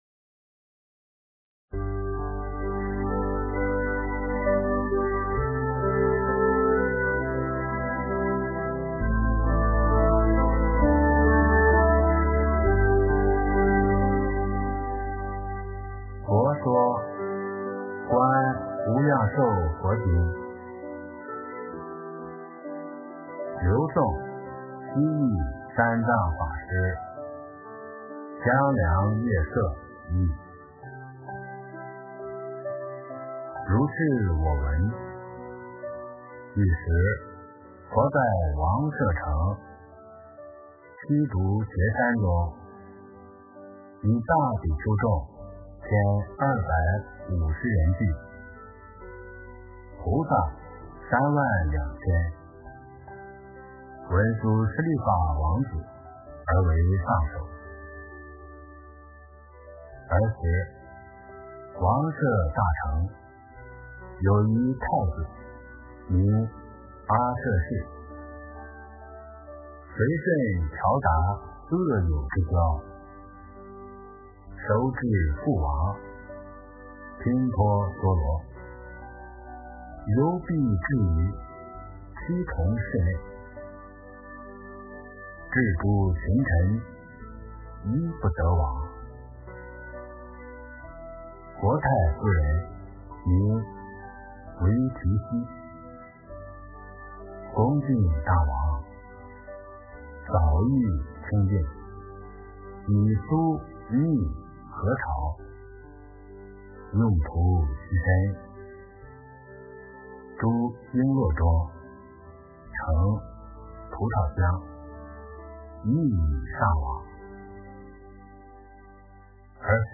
诵经
佛音 诵经 佛教音乐 返回列表 上一篇： 法句经-老品（念诵） 下一篇： 一切如来心秘密全身舍利宝箧印陀罗尼(念诵